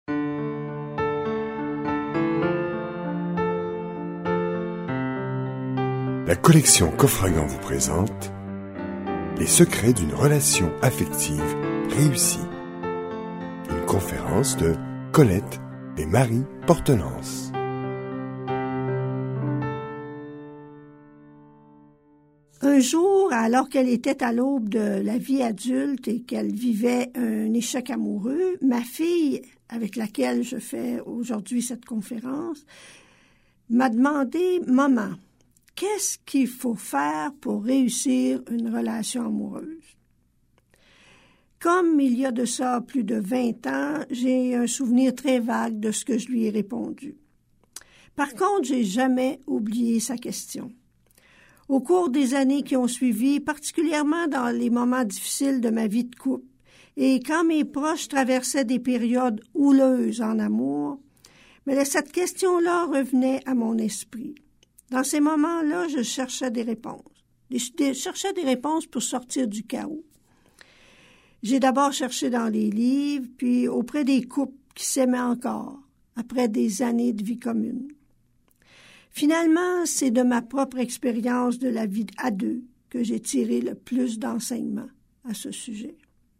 Fortes de leur bagage, de leur expérience et de leurs connaissances sur le sujet, elles vous confieront lors de cette conférence, leurs secrets pour réussir votre vie de couple et vos relations parents-enfants en vous présentant des moyens concrets et réalistes pour prendre soin de vous-même, de l’autre, de la relation amoureuse et de vos relations familiales.